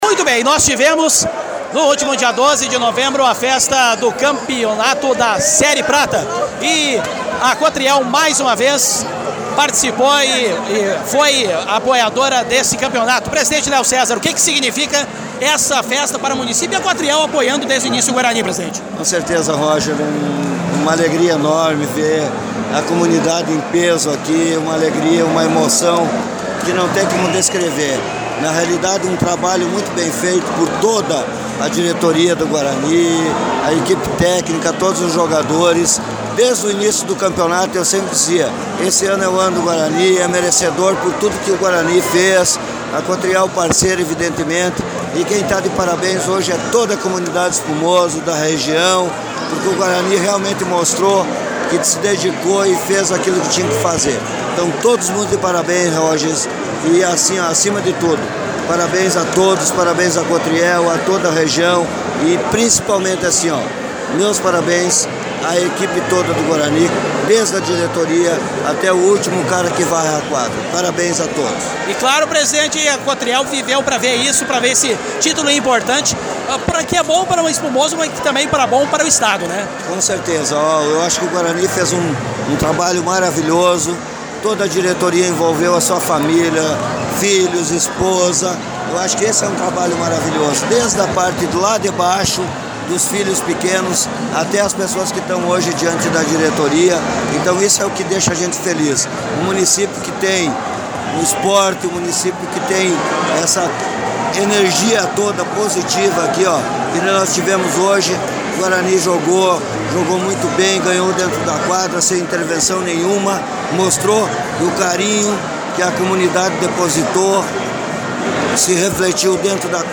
aqui as entrevistas